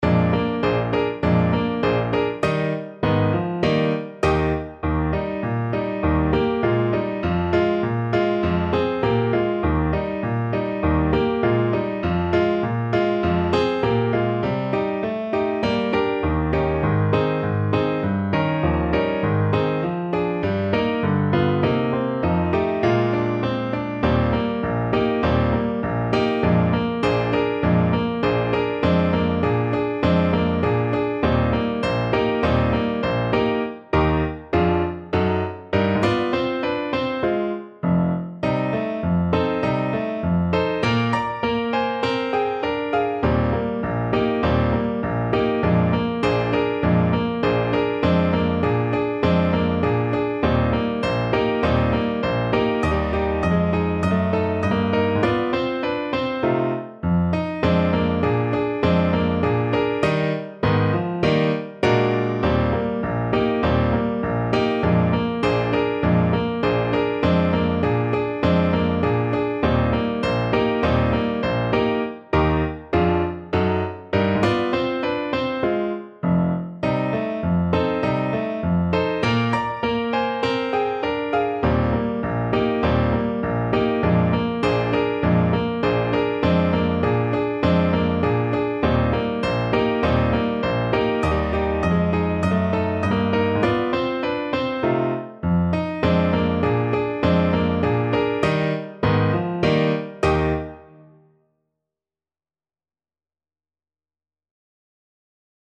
Moderato =c.100
Pop (View more Pop Trombone Music)